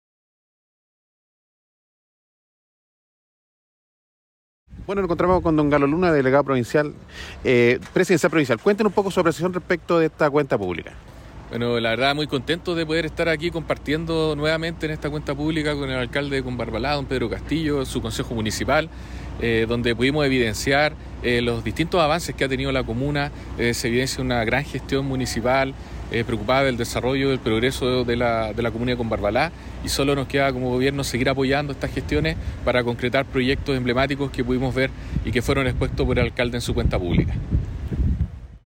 En dependencias de la nueva escuela America de Combarbalá y ante más de un centenar de invitados, se desarrolló el acto oficial de entrega a la comunidad, de una nueva cuenta pública de la gestión 2022 de la Municipalidad de Combarbalá, en una exposición de aproximadamente  3 horas de duración, dónde el Edil Combarbalino, abordó con detalles todo el quehacer de la Municipalidad de Combarbalá.
Como parte de la autoridades regionales invitadas, estaba el Delegado Presidencial Provincial, Galo Luna Penna quién al finalizar esta Cuenta Pública, señaló:
Galo-Luna-Penna-Delegado-Presidencial-Provincia-del-Limari.mp3